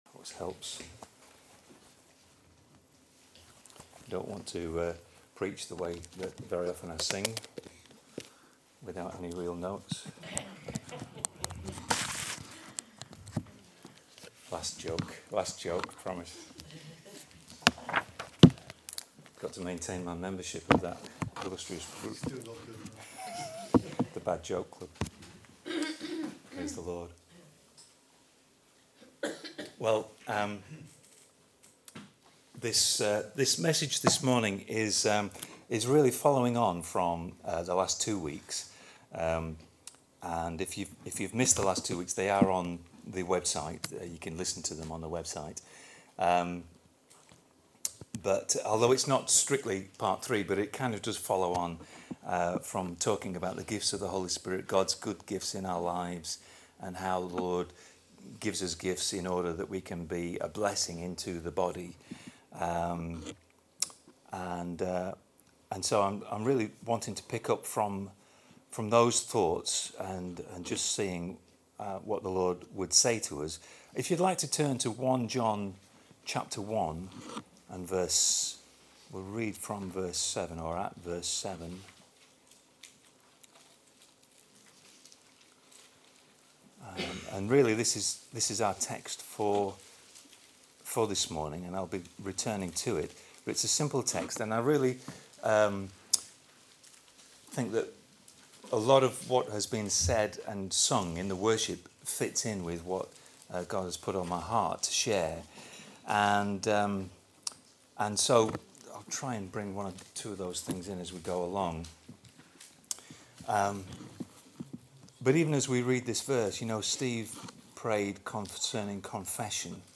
Here is the message from June 10th: